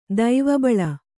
♪ daiva baḷa